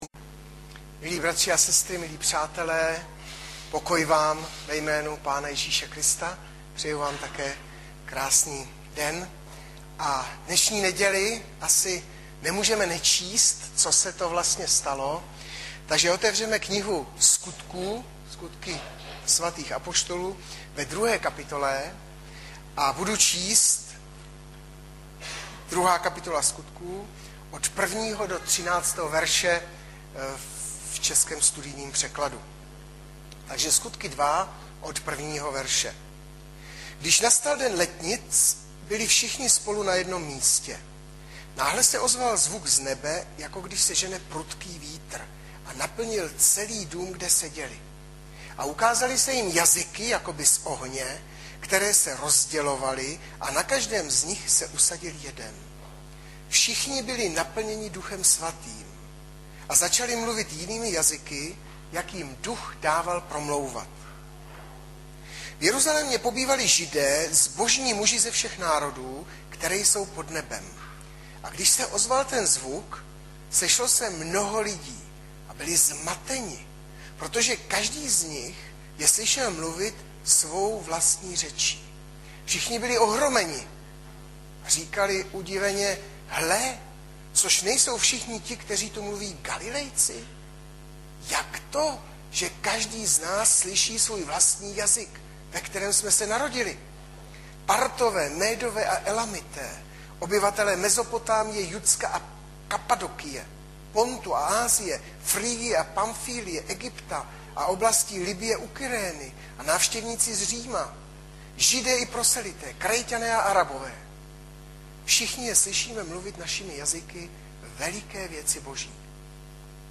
- Sk 2,1-13 Audiozáznam kázání si můžete také uložit do PC na tomto odkazu.